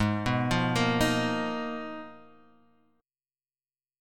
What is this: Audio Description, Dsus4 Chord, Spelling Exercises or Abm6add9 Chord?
Abm6add9 Chord